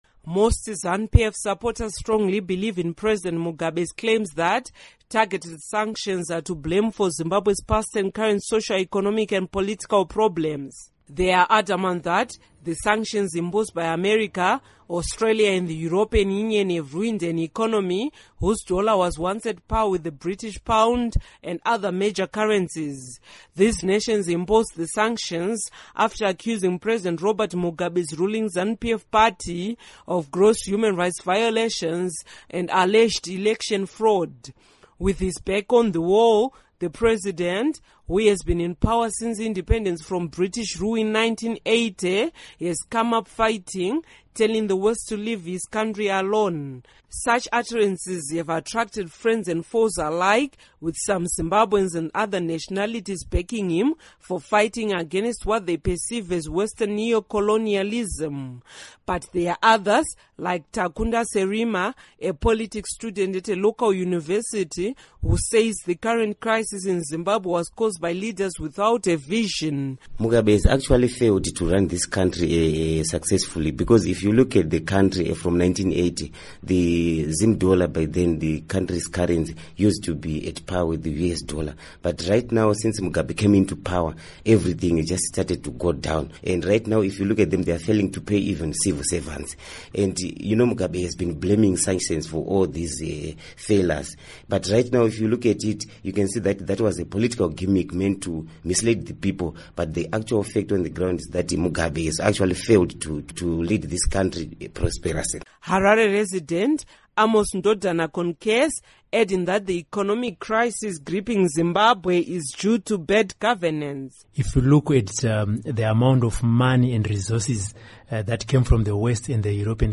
Report on Sanctions